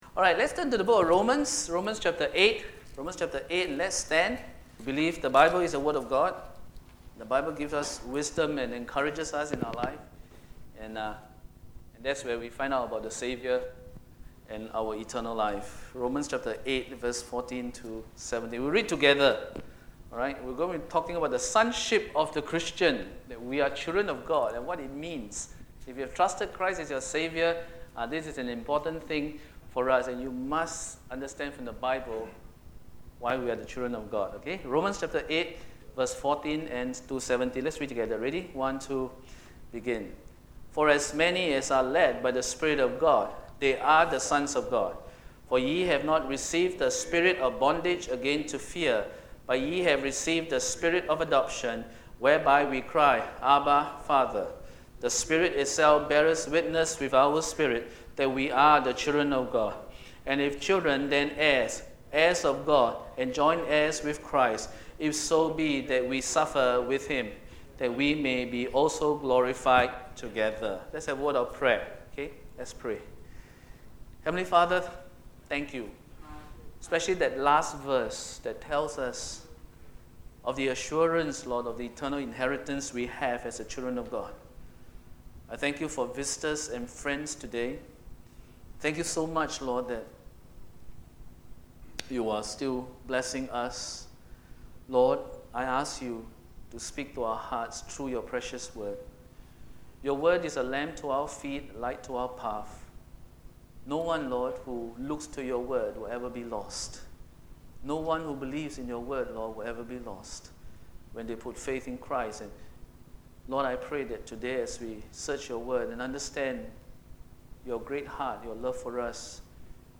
Worship Service 050818 We look at Romans 8:14-17 and learn about The Sonship of the Christian 1.